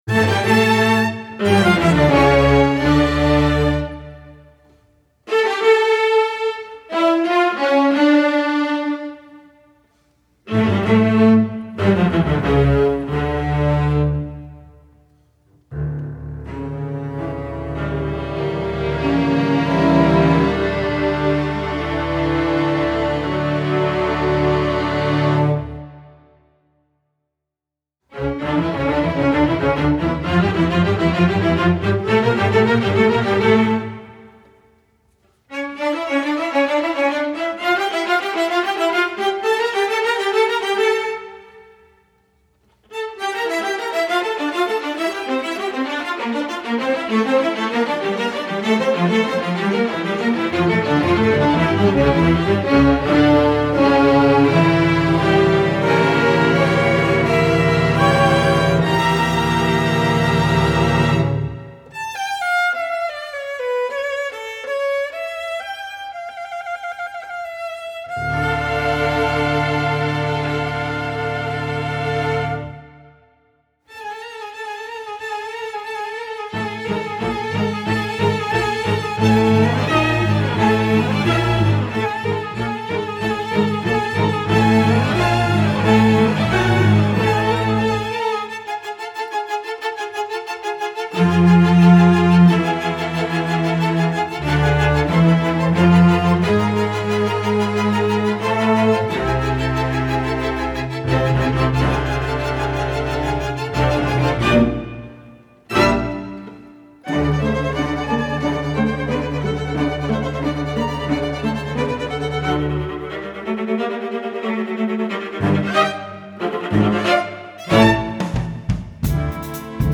Voicing: Orch